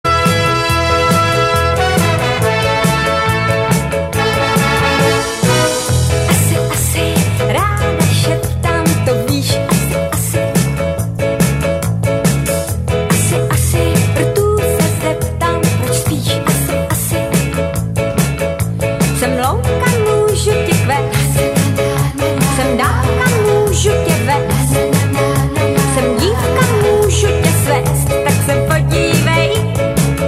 Nahráno ve studiu